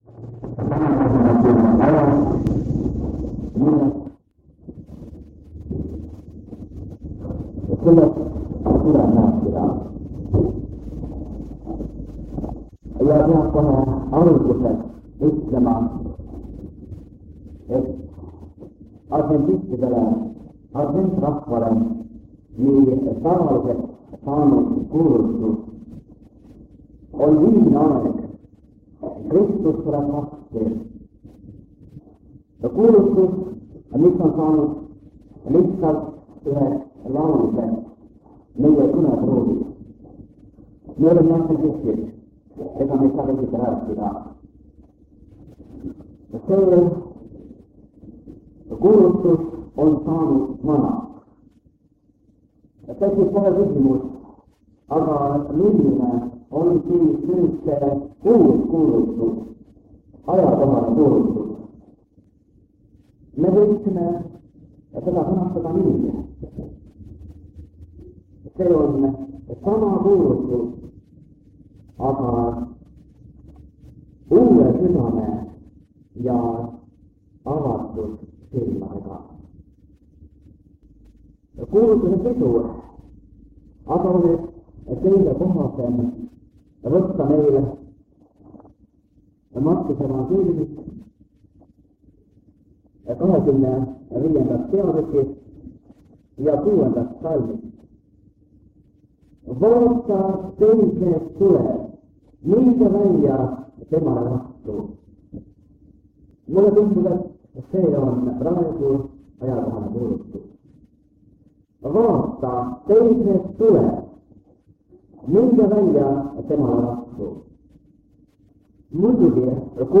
Jutlused
Lindistus väga vanalt lintmaki lindilt aastast 1977... (Salvestis pole väga hea kvaliteediga, aga heade mõtetega siiski)